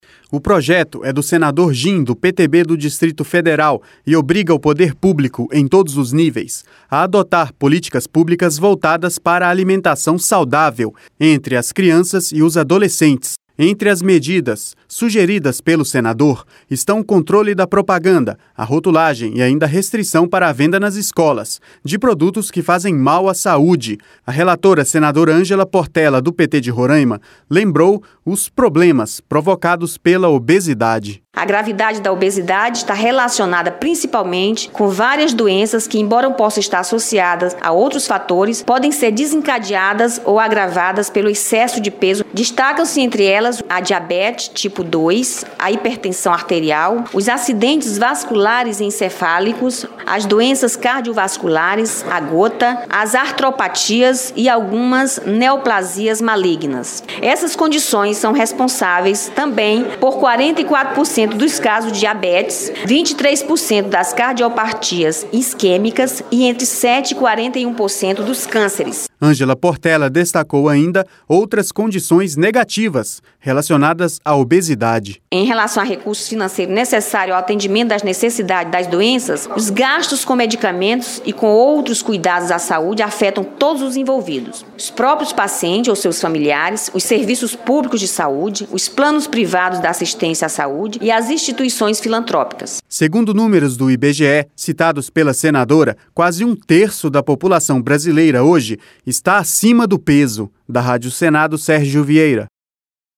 A relatora, senadora Ângela Portela do PT de Roraima, lembrou os problemas provocados pela obesidade.